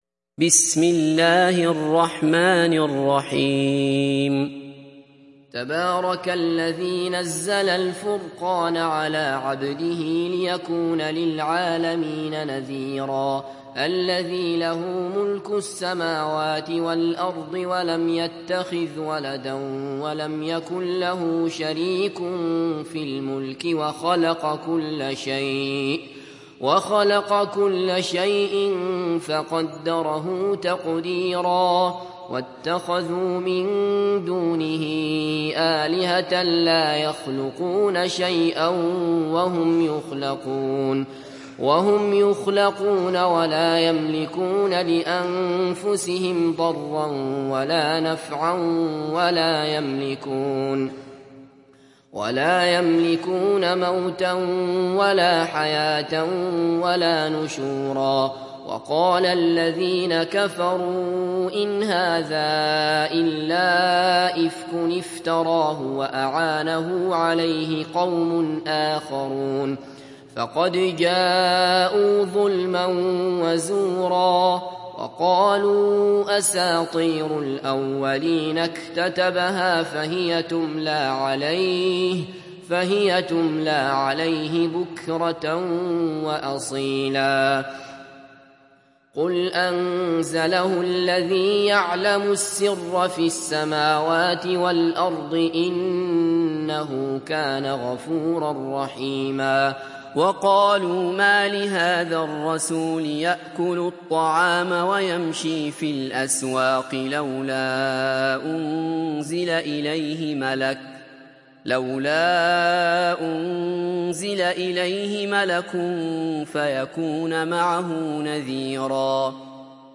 Surat Al Furqan mp3 Download Abdullah Basfar (Riwayat Hafs)